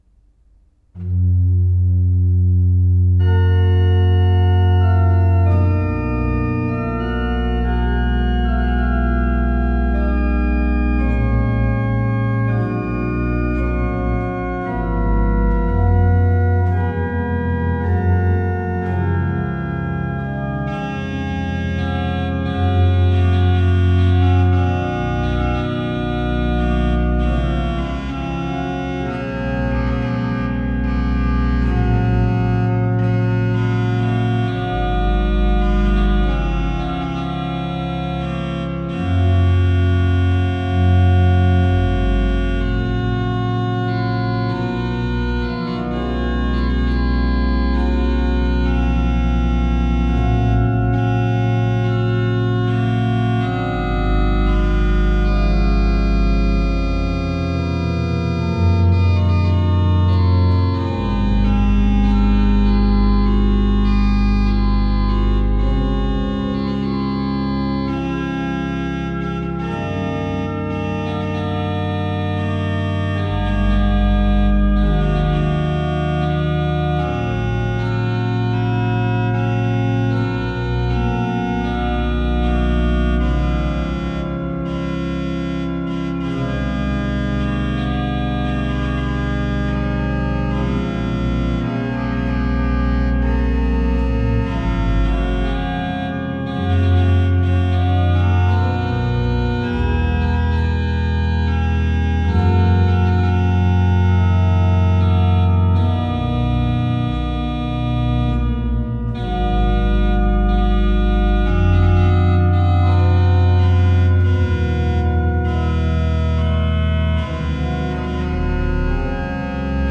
Banque de son de l'orgue Isnard de la basilique de Saint-Maximin-la-Sainte-Baume
À l'orgue Hauptwerk Mixtuur-II, Le Vauroux, enregistrement le 27 octobre 2023